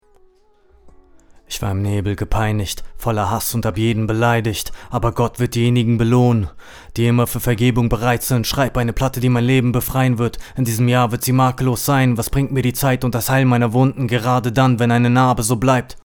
Ohne Low Cut.mp3
Geh noch 5 cm weiter weg vom Mikro, dann ist der Nahbesprechungseffekt noch etwas weniger. Aber die Rohaufnahme ist ok, finde ich.